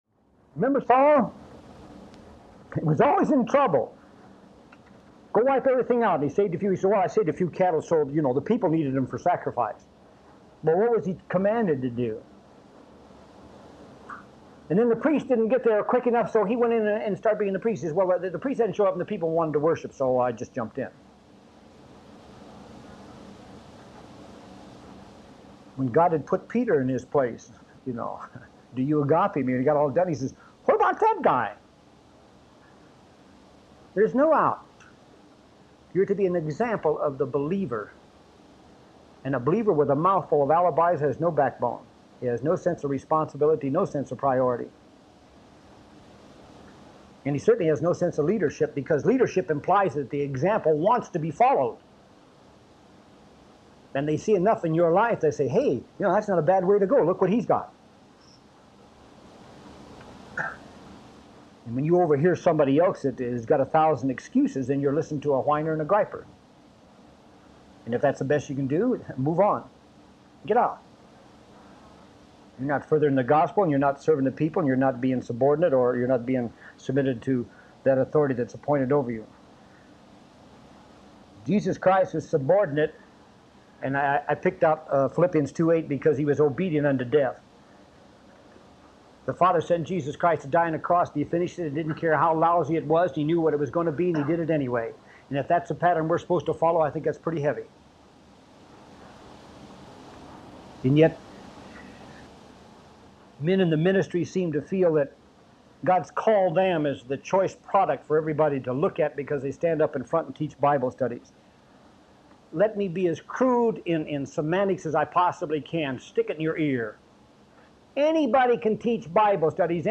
How to be #2 Man download sermon mp3 download sermon notes Welcome to Calvary Chapel Knoxville!